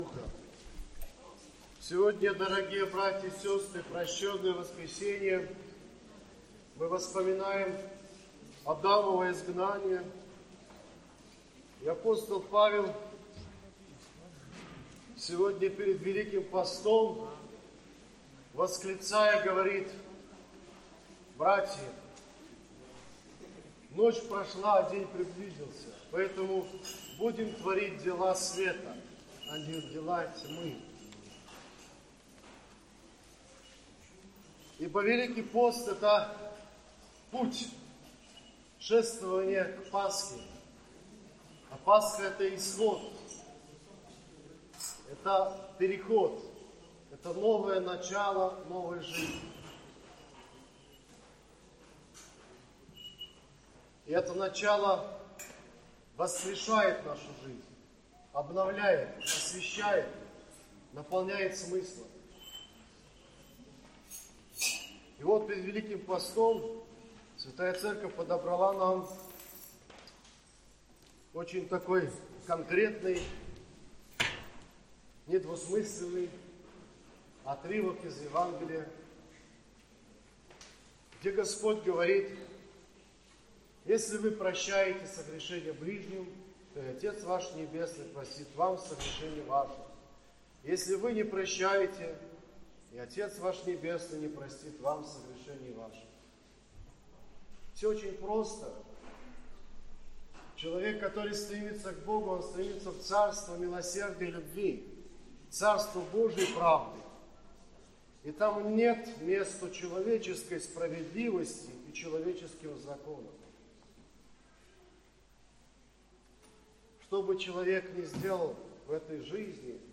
Воскресная проповедь